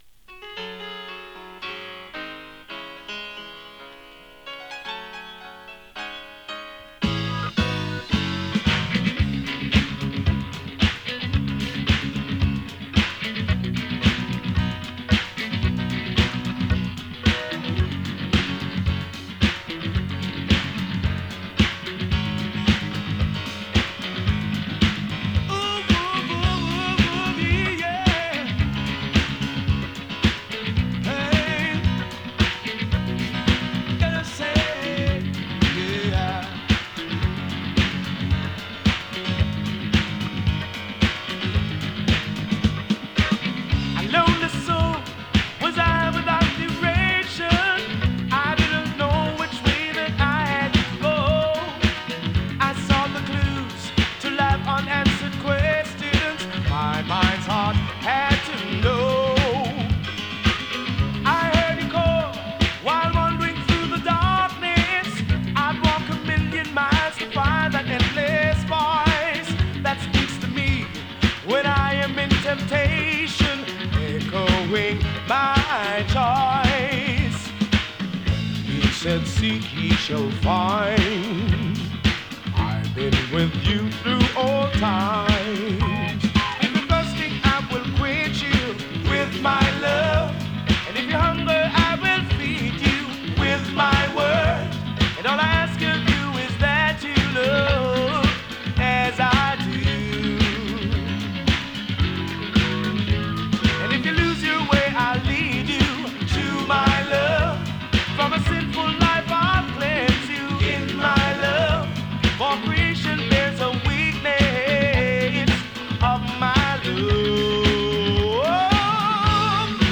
哀愁 レゲエ ディスコ